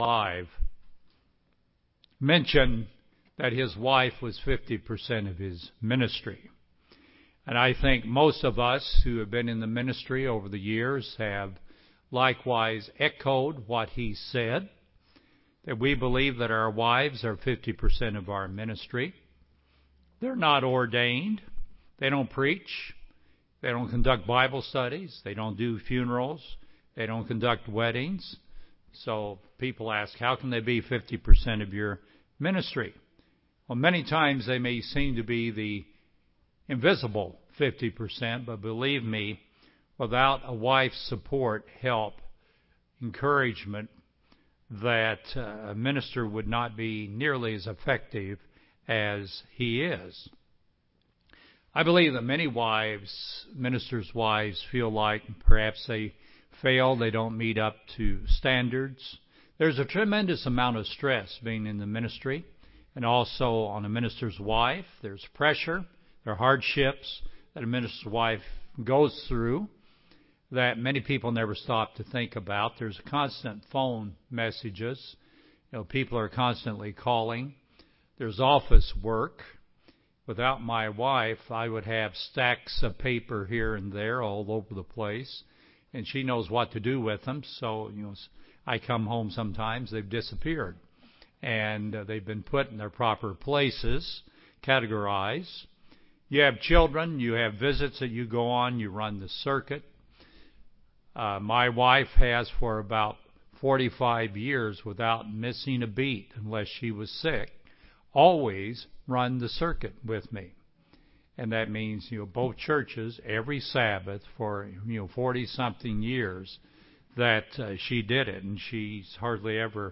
This sermon looks at the way God designed and created the man and the woman, explaining the differences of the man and woman. It focuses on the important of the wife as her husbands "helper".